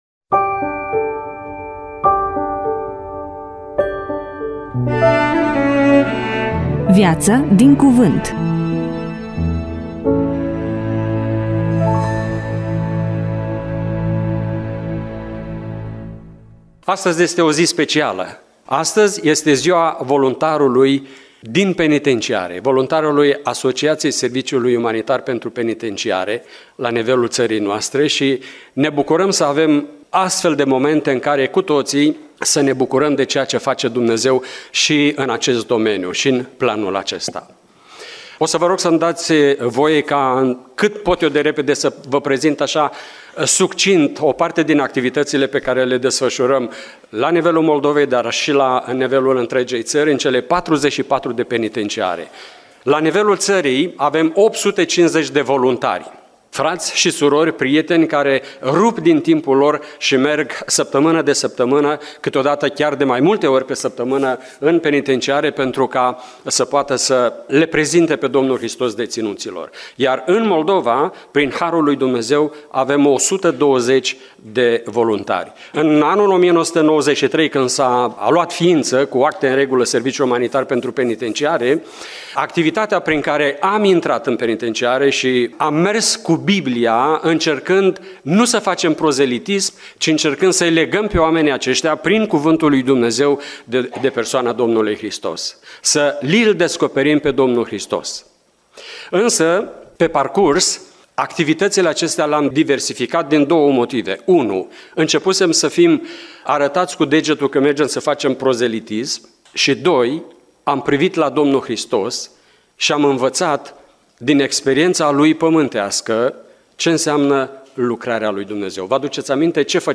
EMISIUNEA: Predică DATA INREGISTRARII: 28.06.2025 VIZUALIZARI: 8